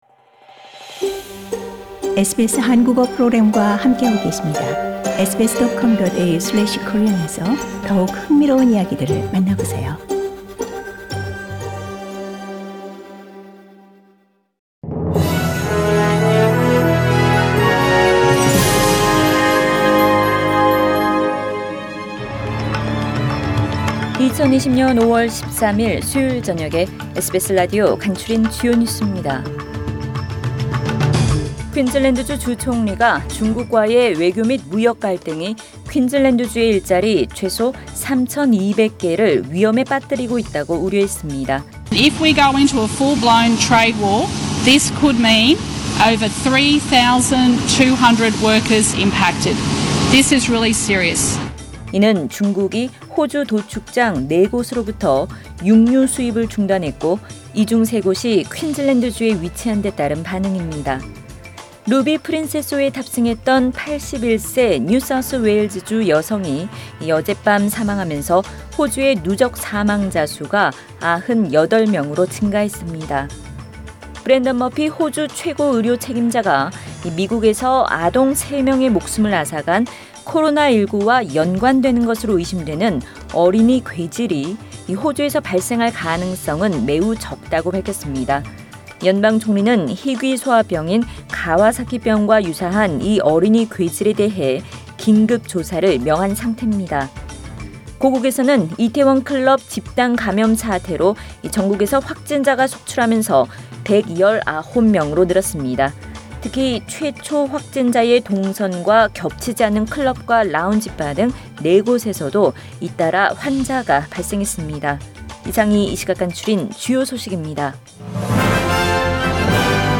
SBS 한국어 뉴스 간추린 주요 소식 – 5월 13일 수요일
2020년 5월 13일 수요일 저녁의 SBS Radio 한국어 뉴스 간추린 주요 소식을 팟 캐스트를 통해 접하시기 바랍니다.